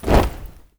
AFROFEET 4-R.wav